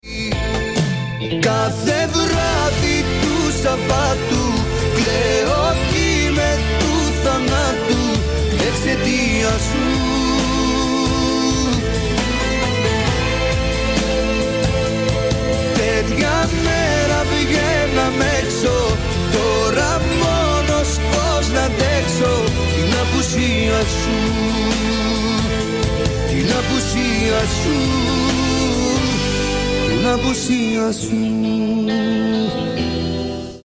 • Качество: 128, Stereo
поп
мужской вокал
спокойные
восточные
баллада
лаика